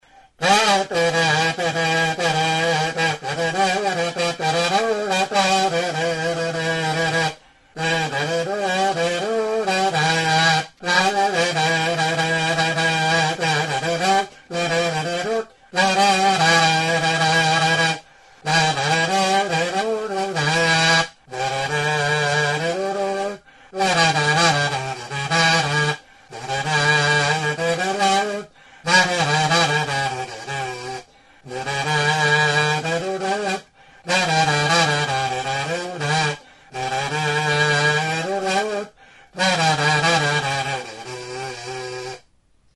Membranófonos -> Mirliton
Grabado con este instrumento.
EUROPA -> EUSKAL HERRIA